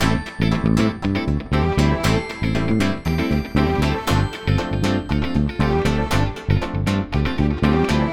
28 Backing PT1.wav